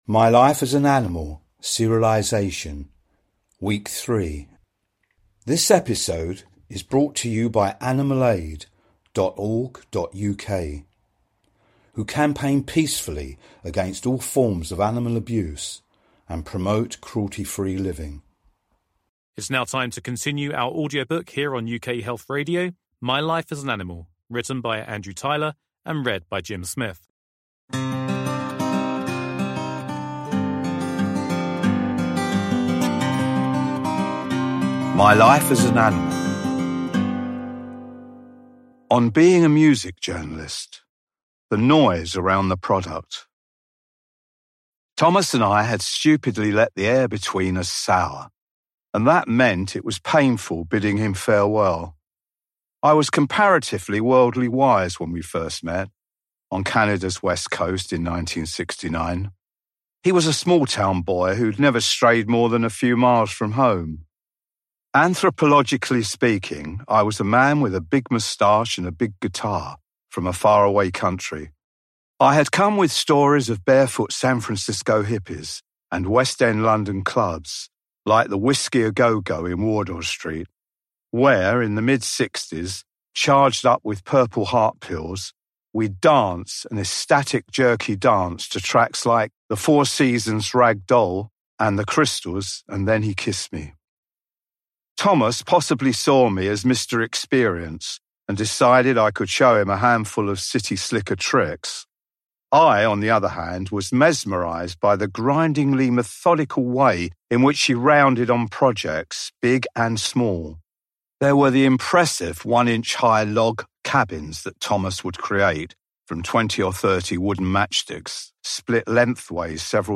UK Health Radio is running its first ever Book serialisation!
It is beautifully written and sensitively voiced.